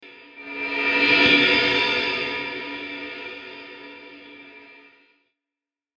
Download Dj transition sound effect for free.
Dj Transition